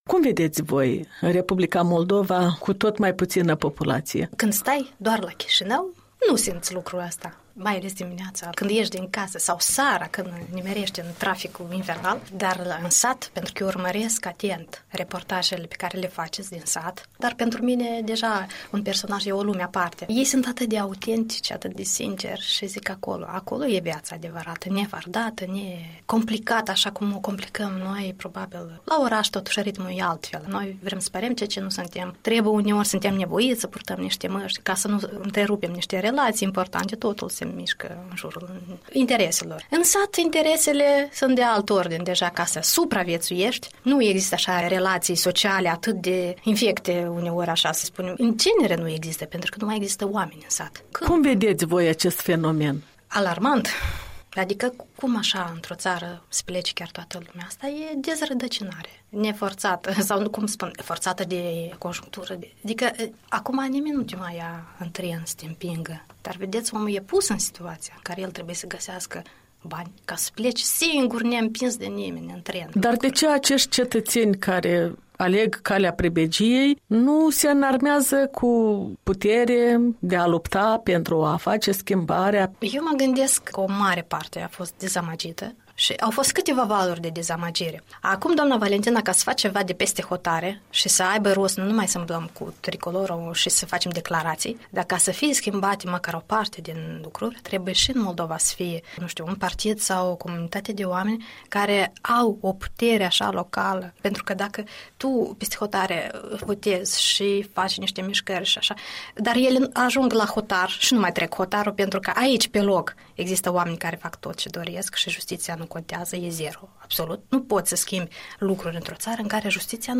În dialog cu o scriitoare stabilită la Bruxelles, despre prezentul și viitorul Republicii Moldova.